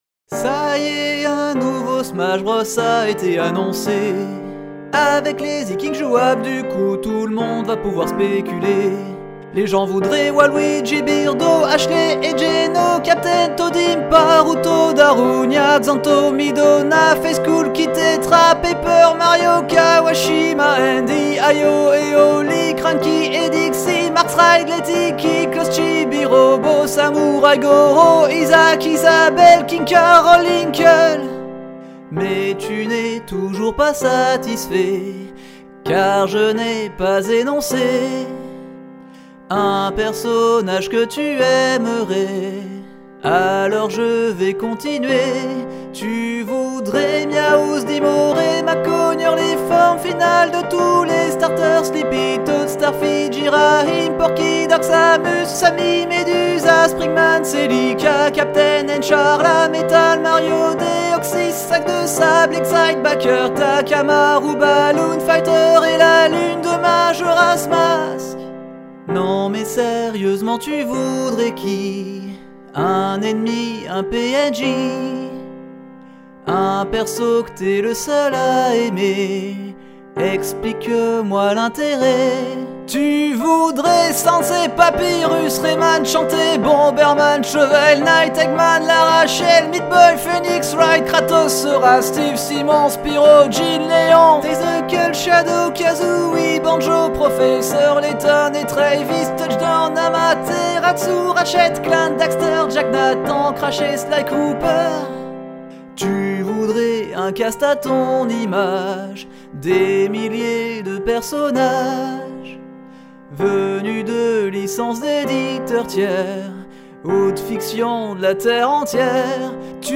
Chanson avec paroles -